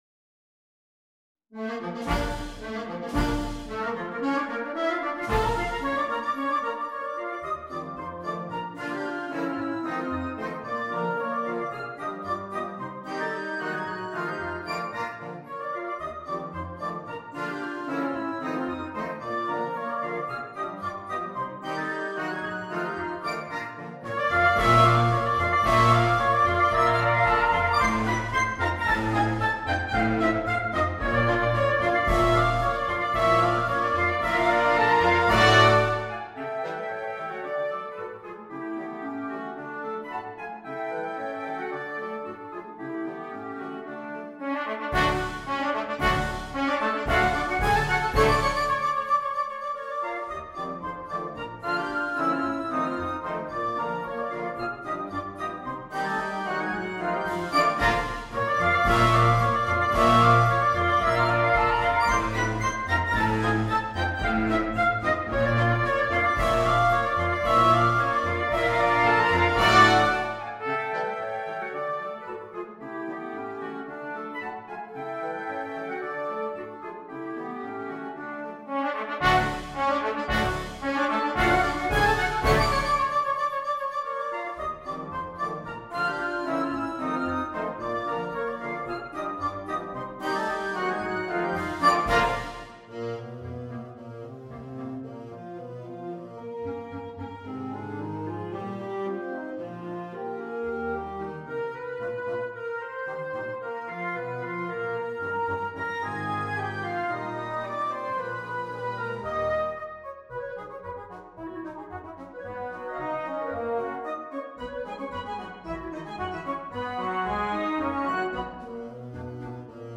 Gattung: Konzertpolka für Blasorchester
Besetzung: Blasorchester